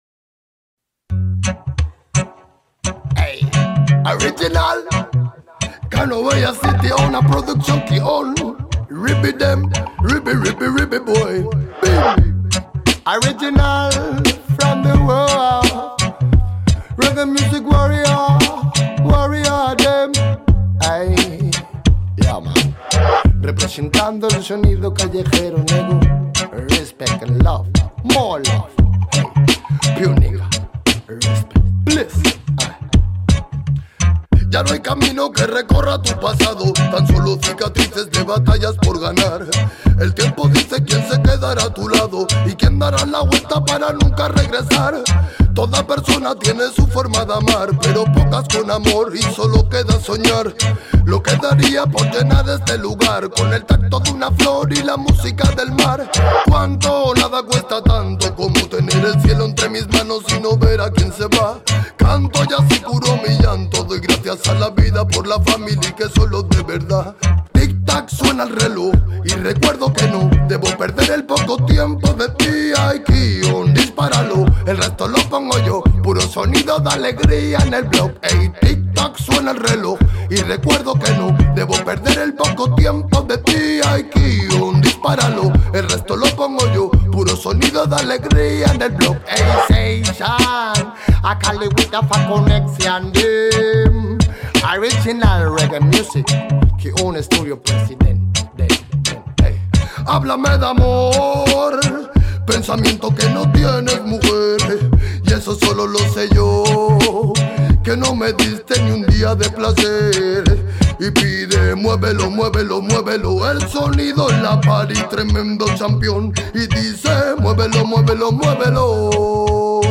al festival Rototom Sunsplash 2024!